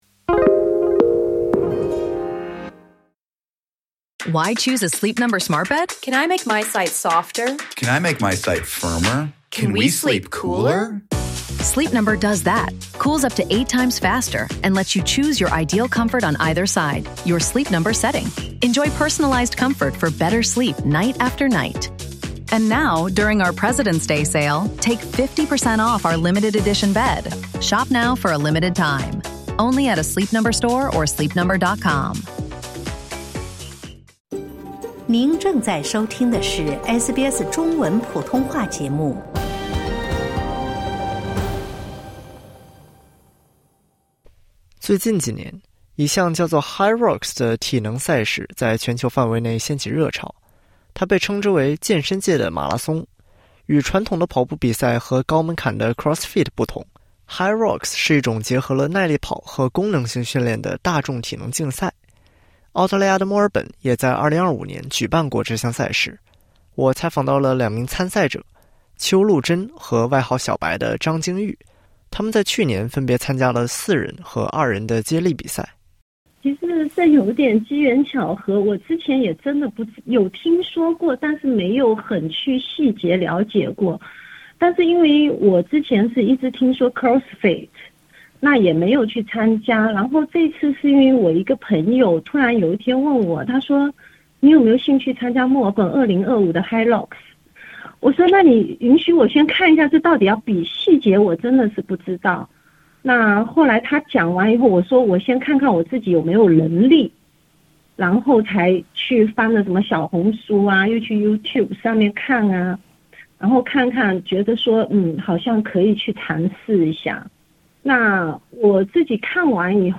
与传统的跑步比赛和高门槛的CrossFit（混合健身），hyrox是一种结合了耐力跑和功能性训练的大众体能竞赛（点击音频，收听完整报道）。